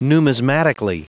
Prononciation du mot numismatically en anglais (fichier audio)
Prononciation du mot : numismatically